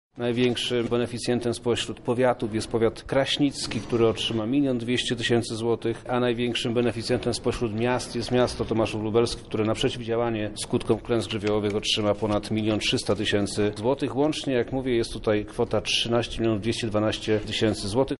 Gmina otrzyma ponad 900 000 złotych – mówi Wojewoda Lubelski Przemysław Czarnek.